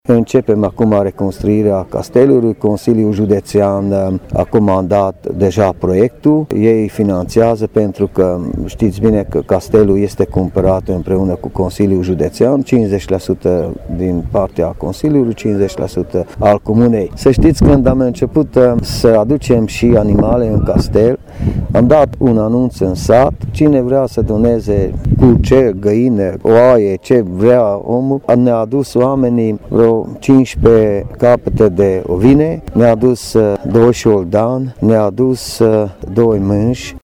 Primarul comunei Bodoc, Fodor István a declarat, că reabilitarea castelului va dura mai mulţi ani, dar între timp s-au realizat o serie de lucrări în fostele grajduri şi a fost creată şi o gospodărie anexă.
a declarat Fodor Istvan.